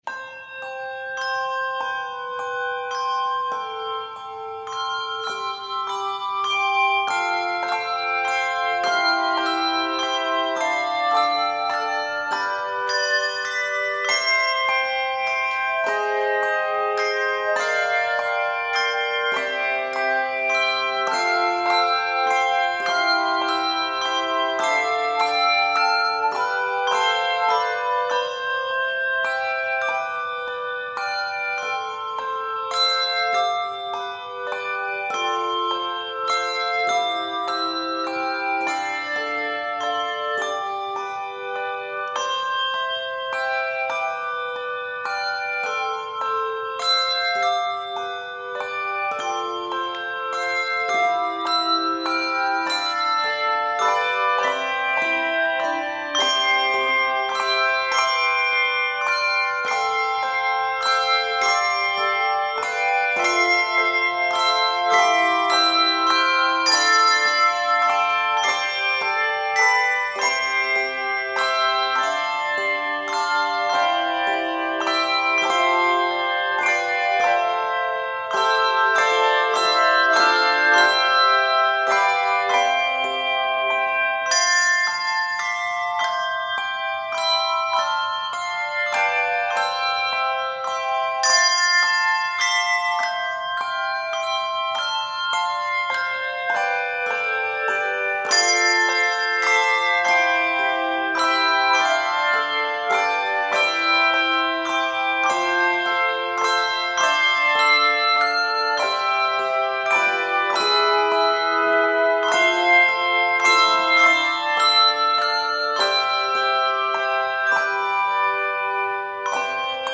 charming, waltz-like original work